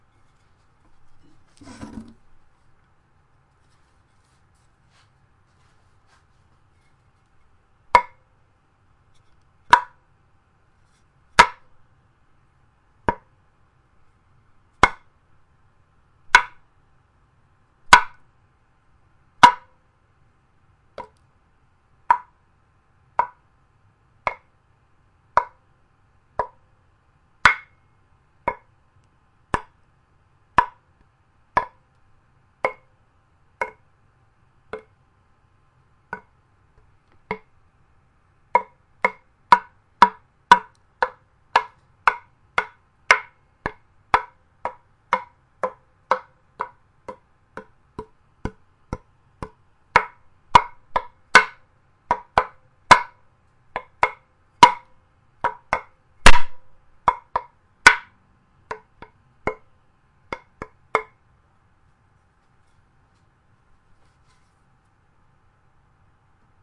车库工具，废品 " 2x4
描述：用2x4木板打2x4木板。
Tag: 2×4 轰的一声 水龙头 打击乐 敲打 冲击